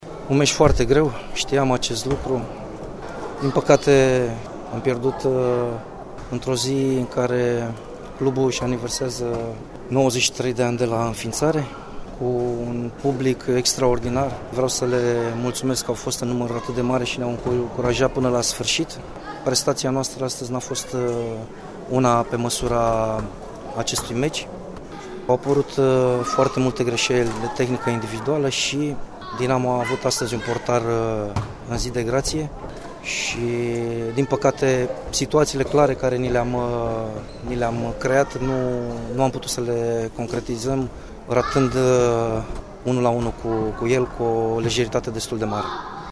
Ascultaţi declaraţiile celor doi antrenori